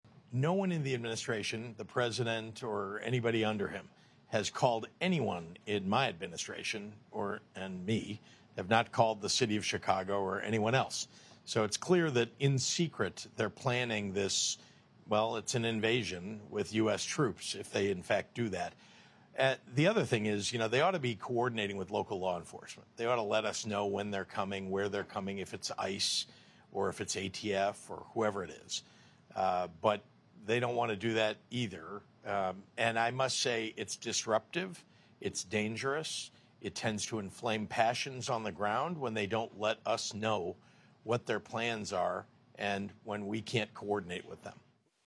In an interview with CBS sound effects free download